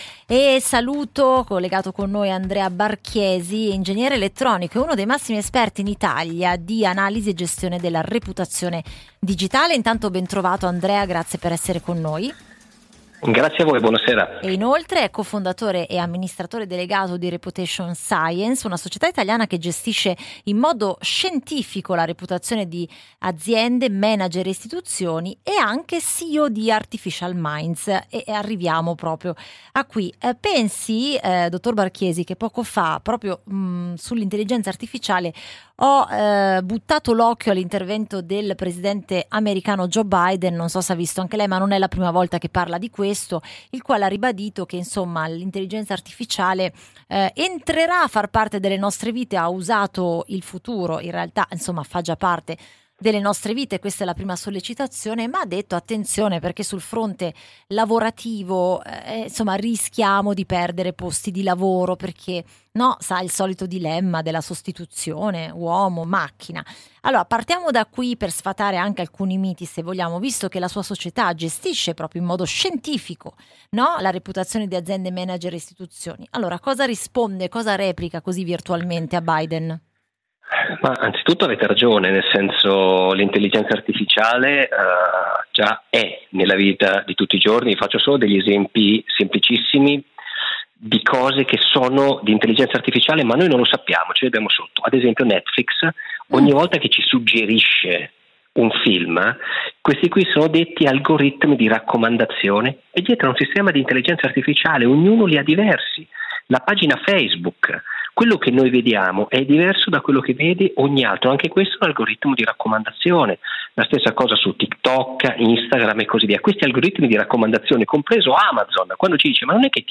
un'intervista nel programma Umami su Giornale Radio